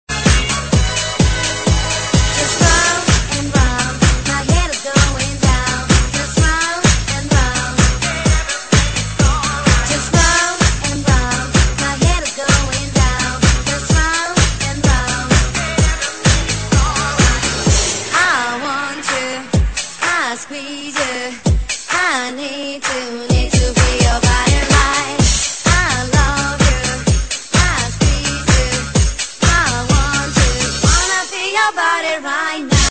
分类: DJ铃声